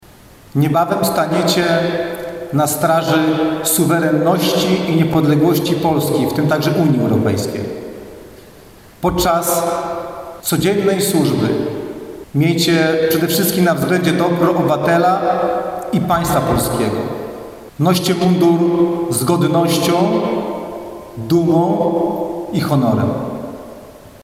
mp3-przemowienie-Komendanta-Oddzialu-SG.mp3